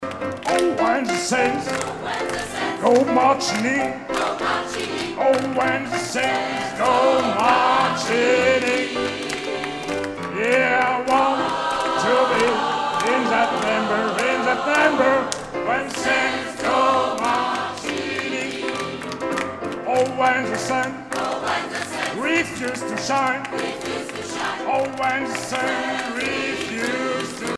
Edition discographique Live
Pièce musicale éditée